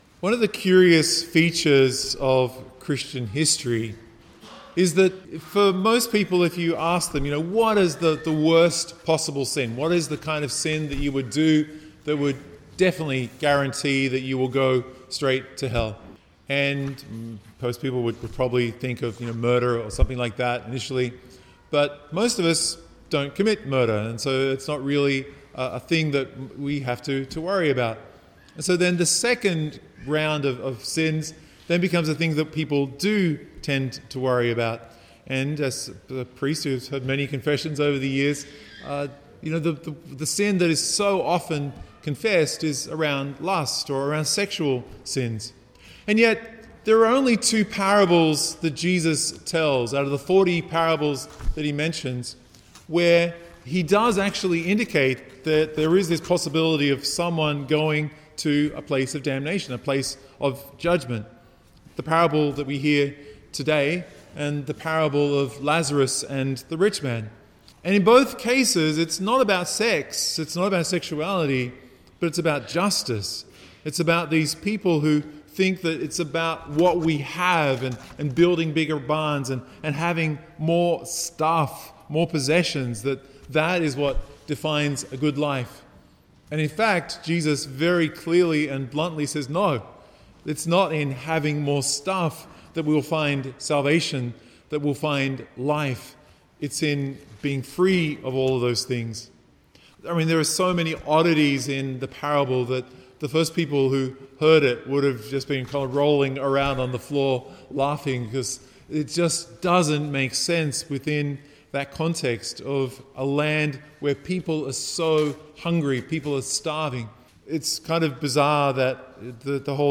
Download or Play MP3 MP3 media (9am Mass)